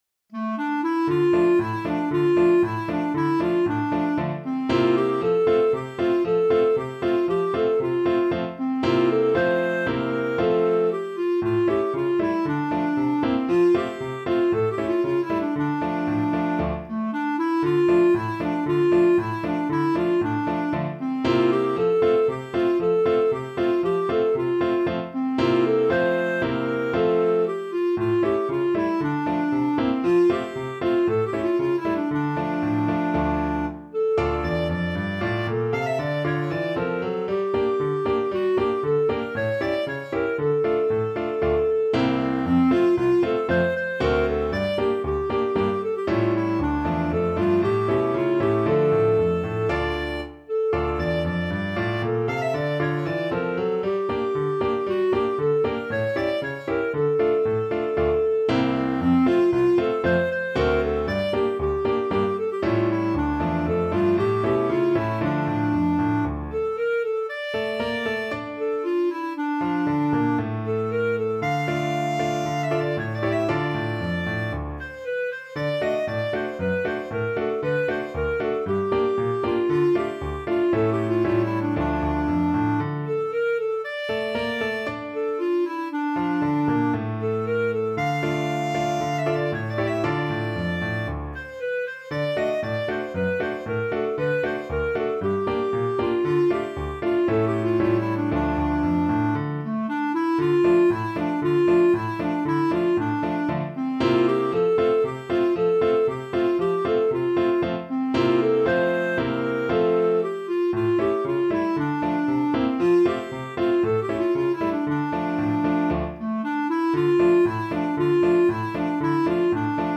Clarinet version
2/4 (View more 2/4 Music)
Allegro =c.116 (View more music marked Allegro)